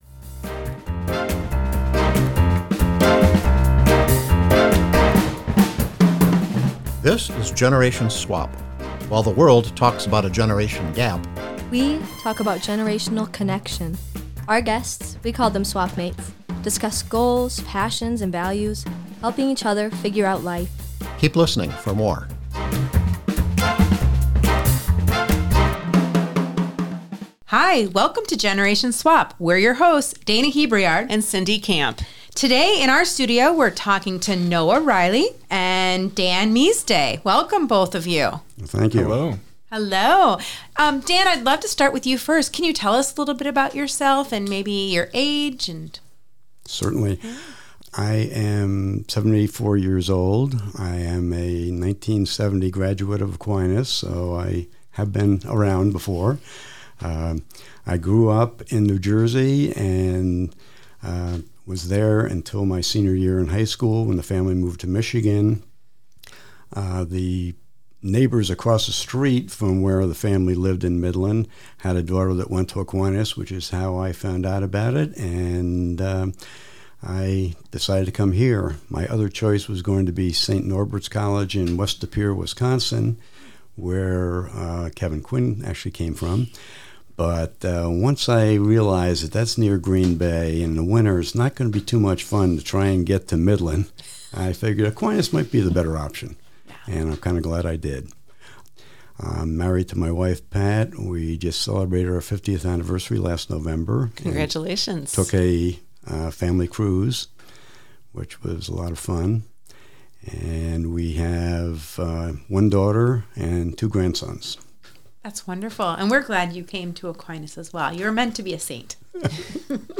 The course capstone project was a Generation Swap podcast interview exploring how generational differences can be a source of strength and growth.